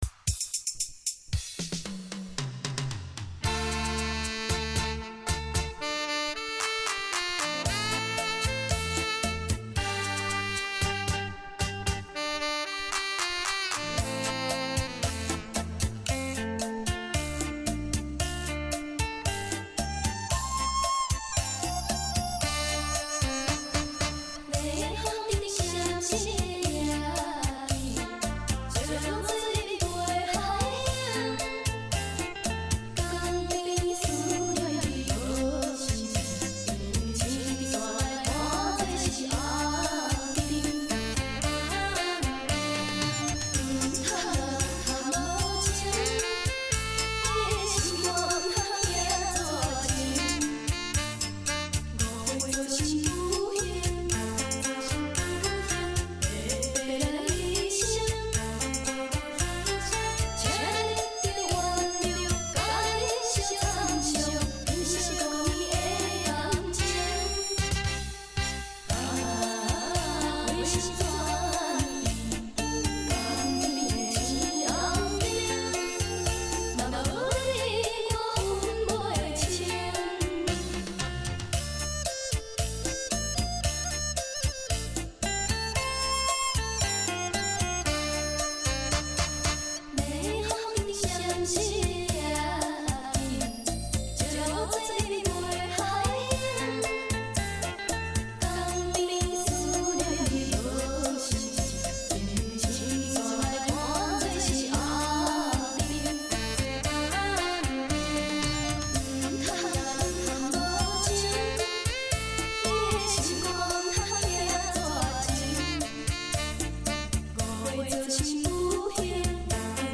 美妙低迷的歌曲加上细腻而独特的唱腔 令你沉醉歌声中